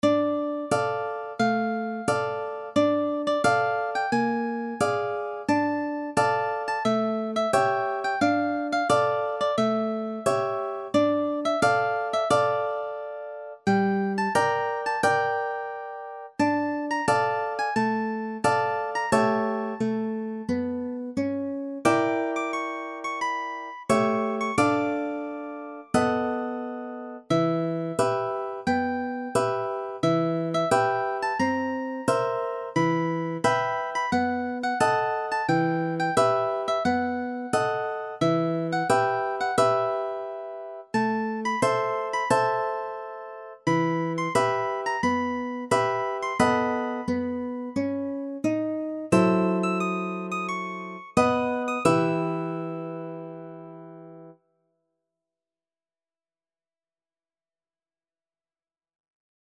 アニメ・ゲーム / アニメ
アップした楽譜は原曲の特徴や魅力を損なわず、ギター入門の方向けにギター二重奏で弾けるようにアレンジしました。
PC演奏（楽譜をそのままMP3にエクスポート）ですが